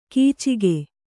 ♪ kīc